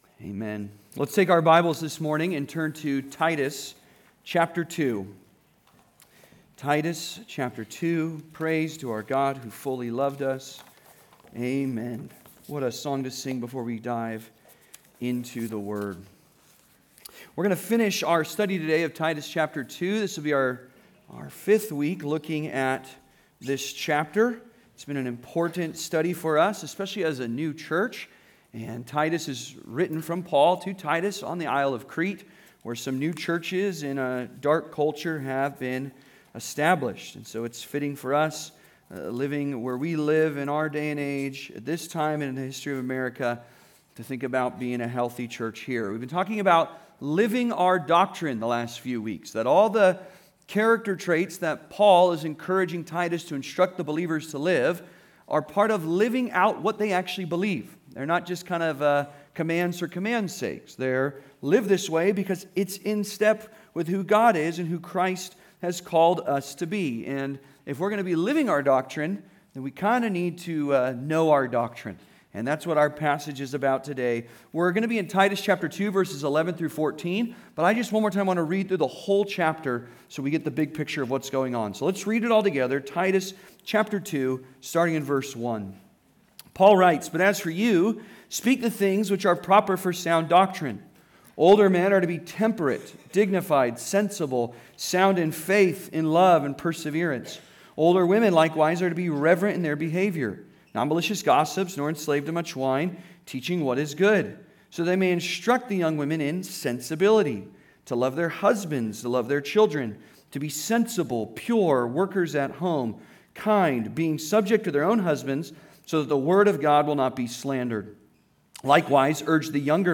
Transformed by Grace (Sermon) - Compass Bible Church Long Beach